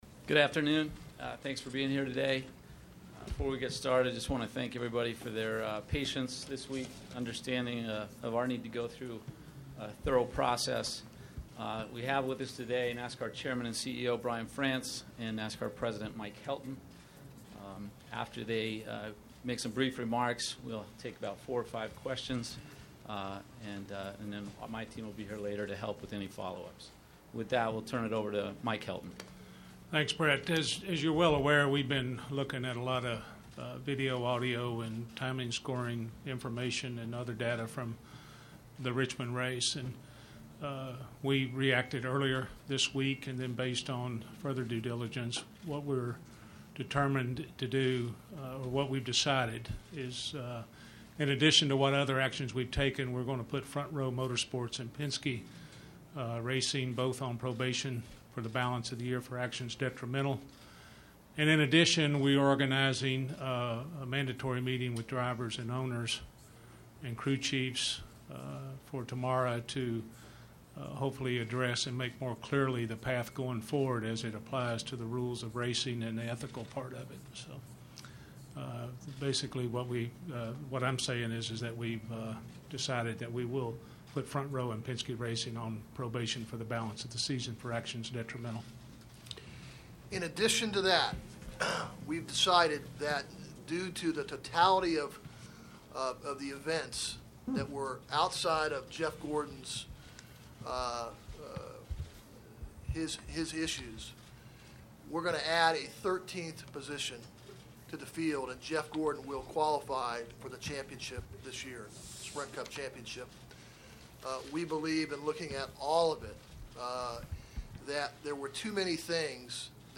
Listen to the audio of Helton/France Announcement